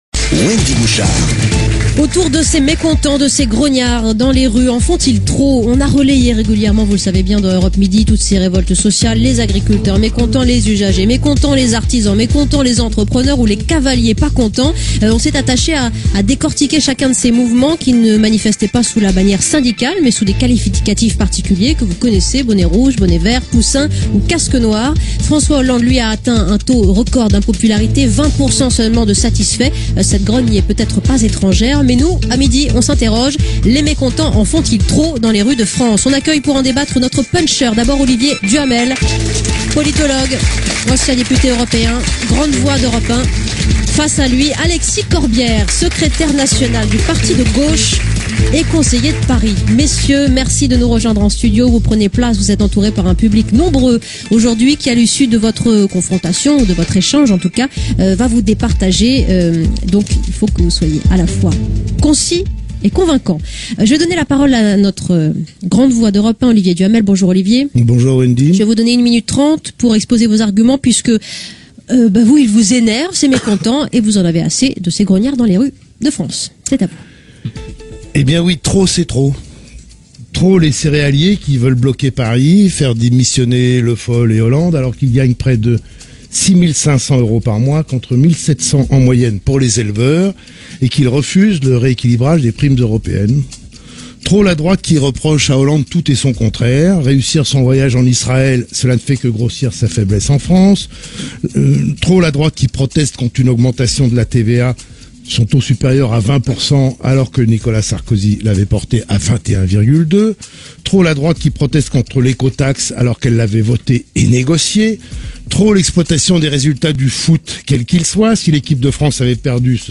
Alexis Corbière était invité à un face à face avec Olivier Duhamel, lors de l’émission Europe 1 Midi animée par Wendy Bouchard le 22 novembre 2013.